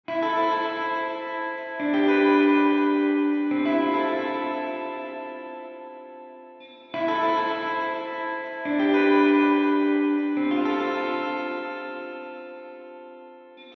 Der Plattenhall zeichnet sich durch einen dichten, glatten Klang aus.
Nun hören Sie eine Gitarre mit einem Plattenhall:
Gitarre-Plate-Hall.mp3